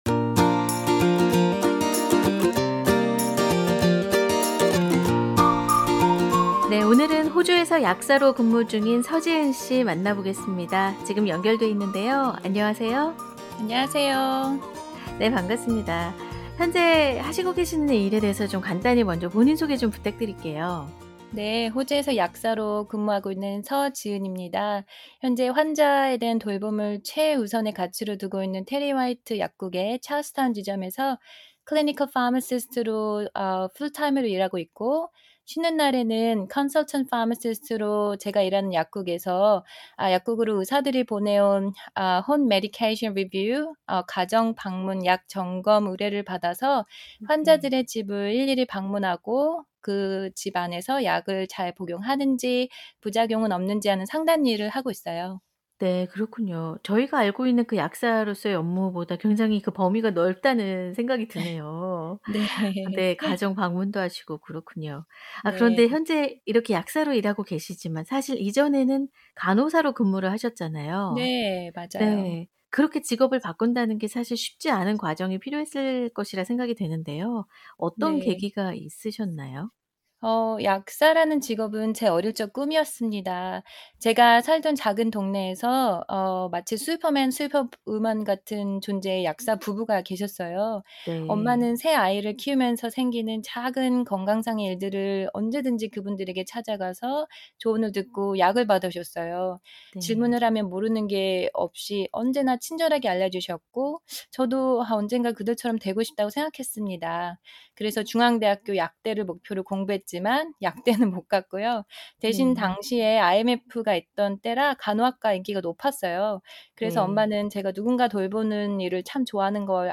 JOB인터뷰